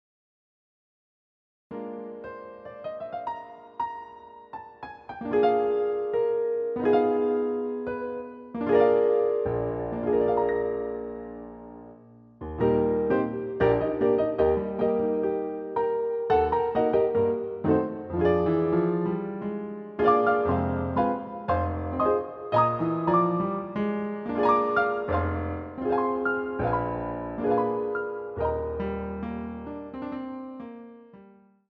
CD quality digital audio Mp3 file recorded
using the stereo sampled sound of a Yamaha Grand Piano.